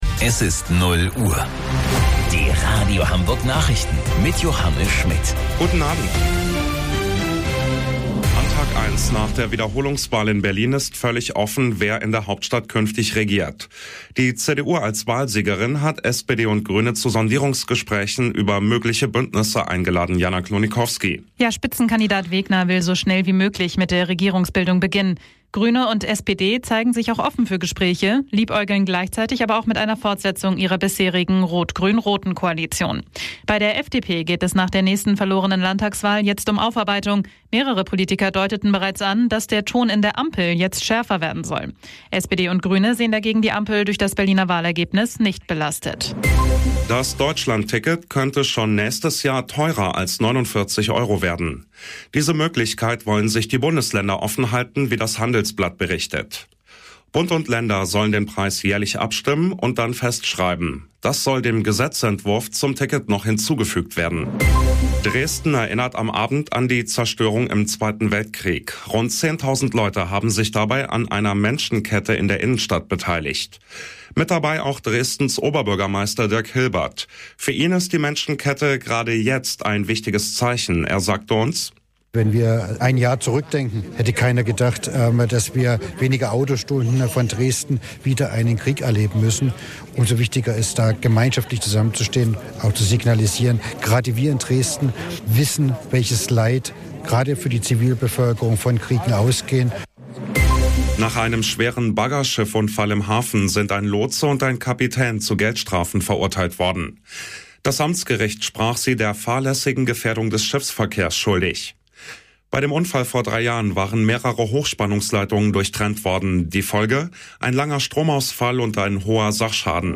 Radio Hamburg Nachrichten vom 13.09.2022 um 06 Uhr - 13.09.2022